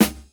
• 2000s Clean Snare Single Hit D Key 27.wav
Royality free snare drum sample tuned to the D note. Loudest frequency: 2494Hz